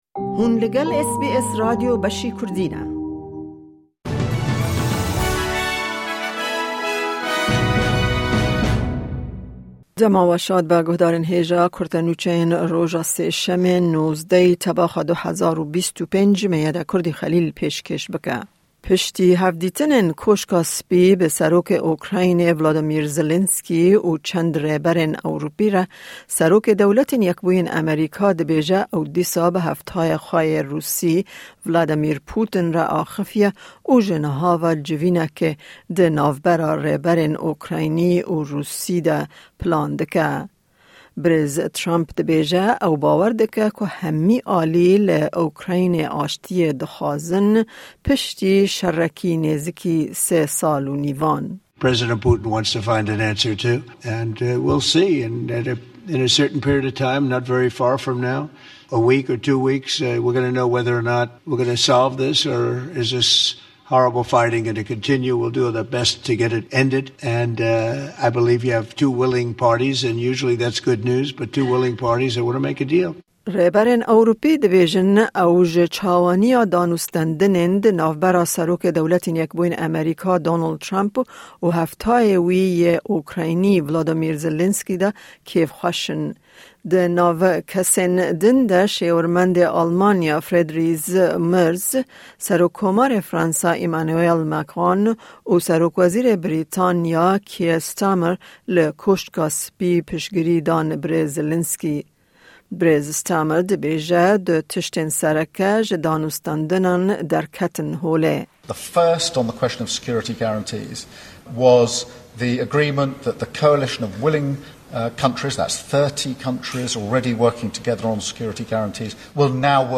Kurte Nûçeyên roja Sêşemê 19î Tebaxa 2025